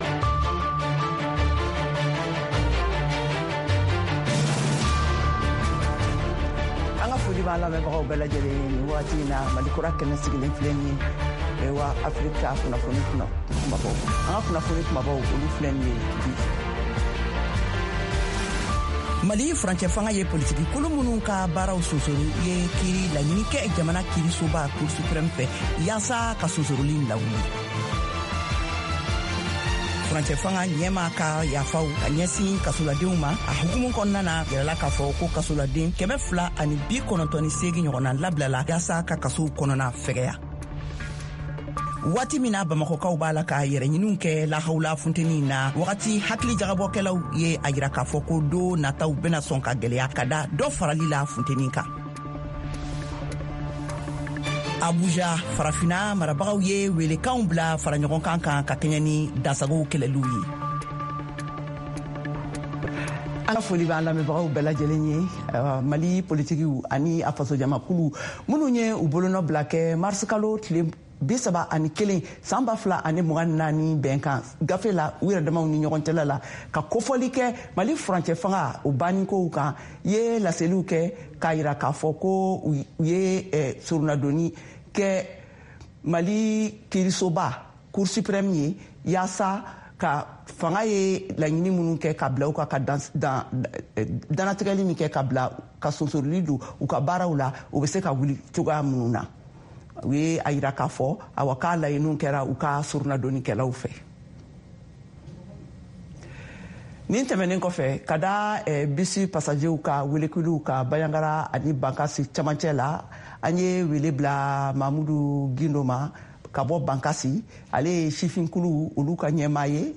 10 min News French